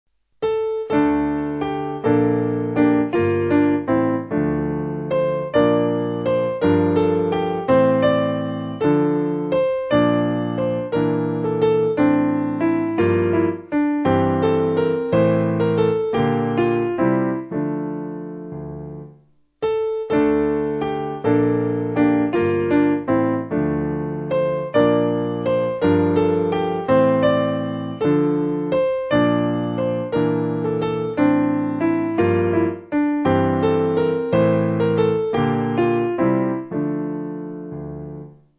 Simple Piano
(CM)   5/Dm